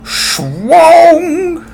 infinitefusion-e18/Audio/SE/Cries/BISHARP.mp3 at releases-April